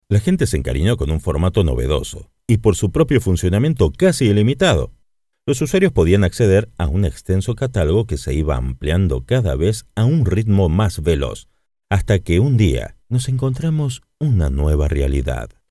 Masculino
Espanhol - Argentina